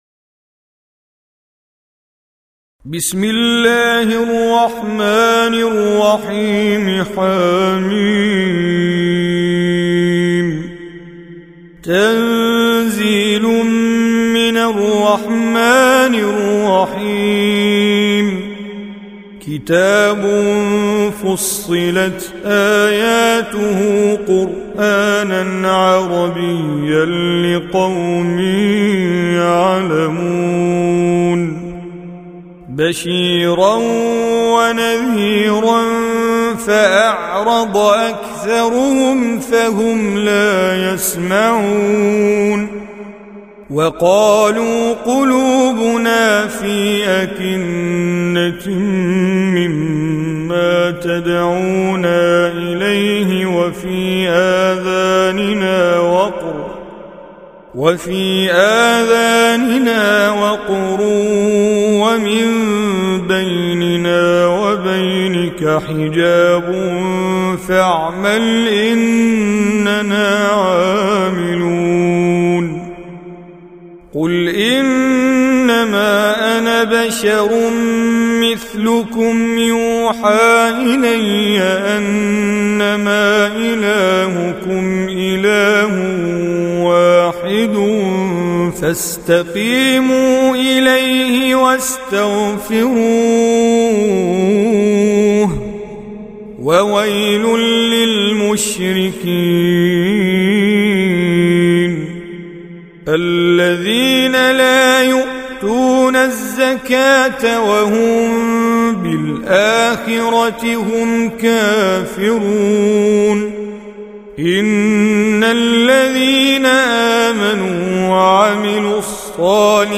41. Surah Fussilat سورة فصّلت Audio Quran Tajweed Recitation
Surah Repeating تكرار السورة Download Surah حمّل السورة Reciting Mujawwadah Audio for 41.